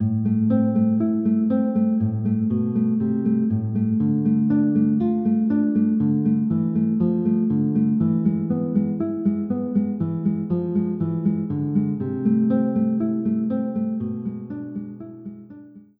guitar chords